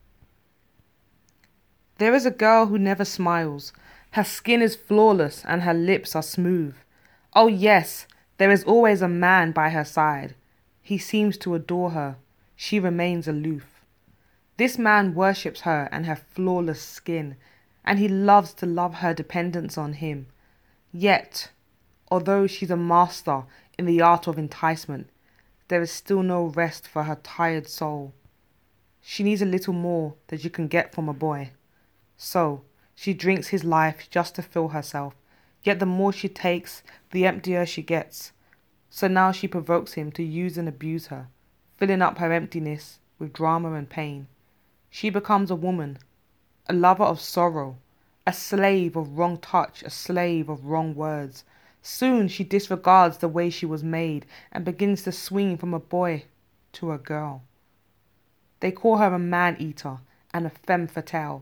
Meanwhile, please enjoy this short snippet with her powerful delivery of the story about a girl who never smiled… until she did.